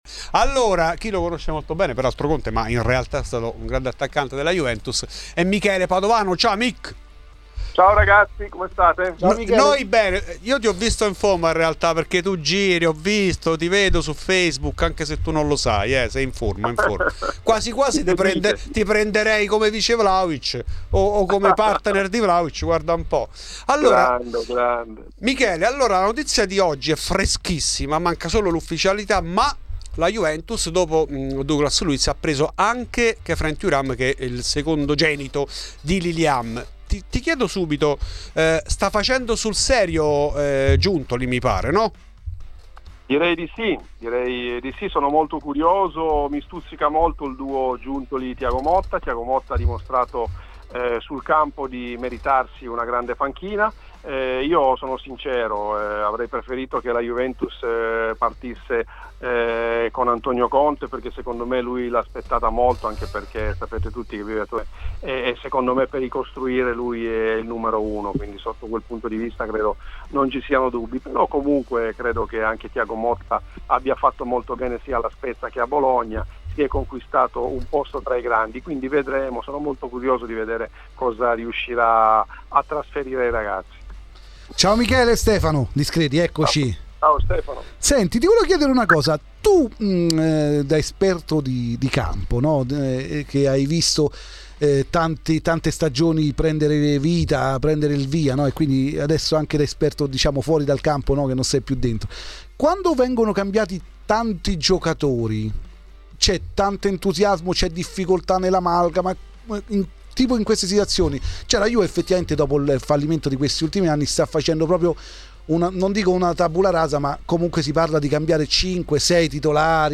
In ESCLUSIVA a Fuori di Juve Michele Padovano, attaccante bianconero nel biennio 1995/1997, convinto che per la corsa scudetto bisogna fare attenzione al Napoli di Conte.
Nel podcast l'intervento integrale